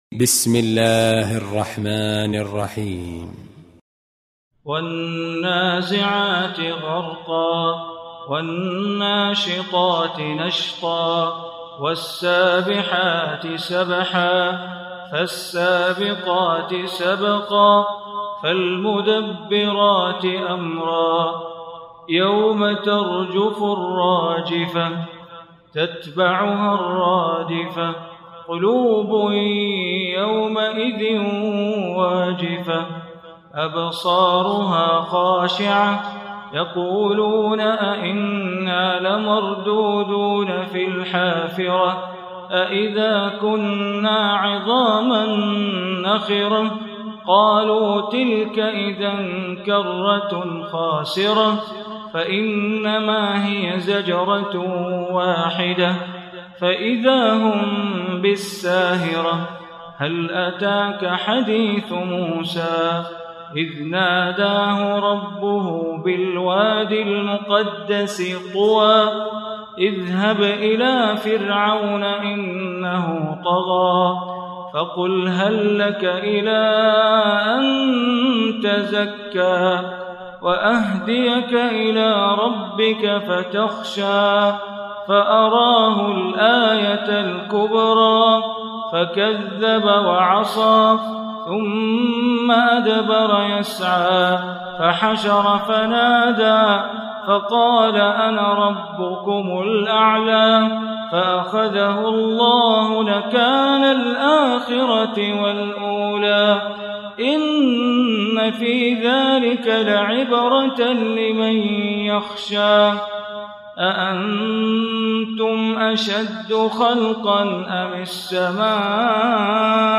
Surah Naziat Recitation by Sheikh Bandar Baleela
Surah Naziat, listen online mp3 tilawat / recitation in Arabic recited by Imam e Kaaba Sheikh Bandar Baleela.